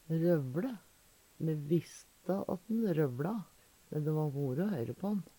røvLe - Numedalsmål (en-US)